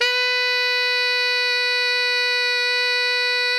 Index of /90_sSampleCDs/Giga Samples Collection/Sax/GR8 SAXES MF
TENOR FF-B4.wav